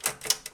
Tecla de retroceso en una máquina de escribir
máquina de escribir
Sonidos: Oficina